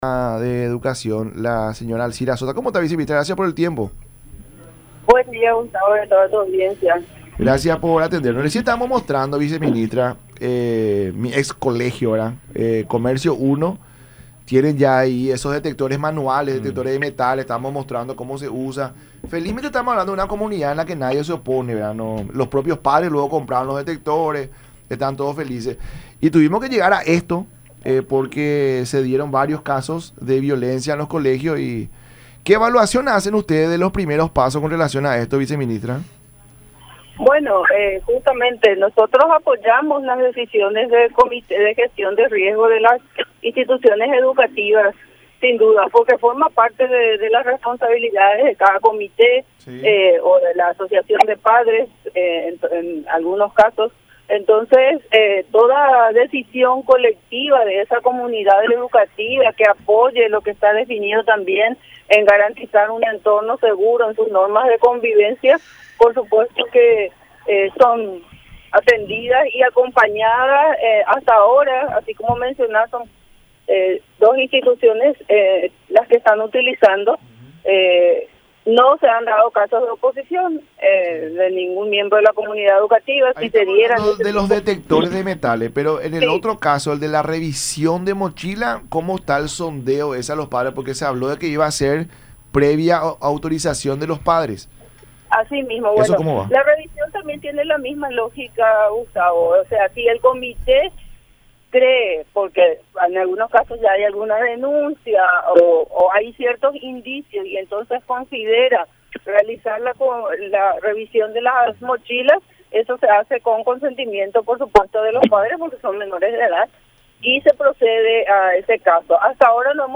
Alcira Sosa, viceministra de Educación.
“Apoyamos las decisiones de Comités de Gestión de Riesgos de las instituciones educativas porque forman parte de sus responsabilidades. Toda decisión de la comunidad educativa que apoye garantizar un entorno seguro en las normas de convivencia, por supuesto que son atendidas y acompañadas”, dijo Sosa en conversación con La Mañana De Unión por Unión TV y radio La Unión.